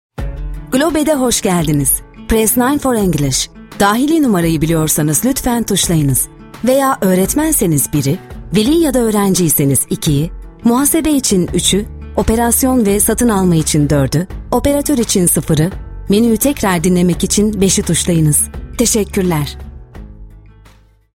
IVR Announcement Voice Over
IVR Announcement Voice Over Service